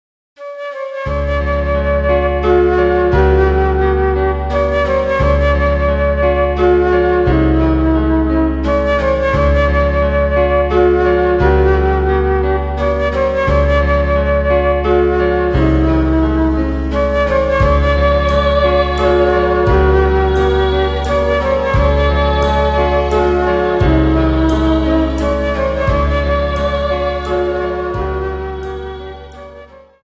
Dance: Slow Waltz